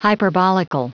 Prononciation du mot hyperbolical en anglais (fichier audio)
Prononciation du mot : hyperbolical